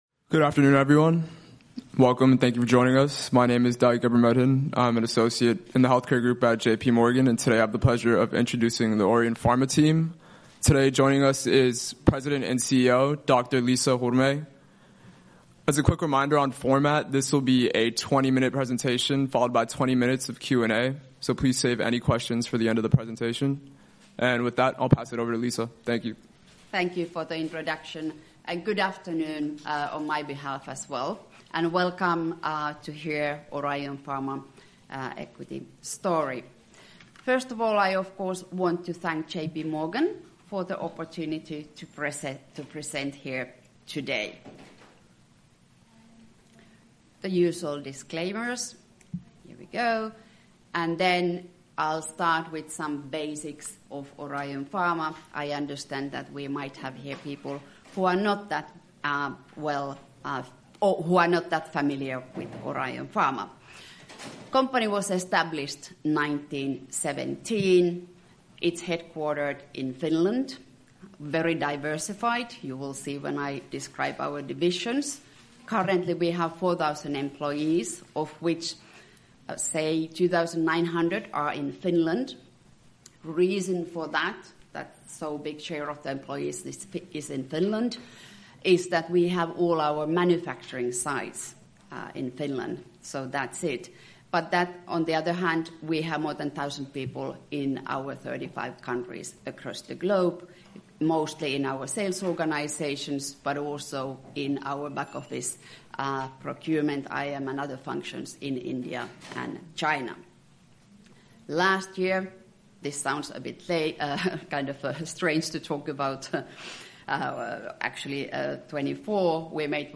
Orion Corporation attended the 44th Annual J.P. Morgan Healthcare Conference on January 12-15, 2026 in San Francisco, USA.
orion-pharma-jpm-2026-audiocast.mp3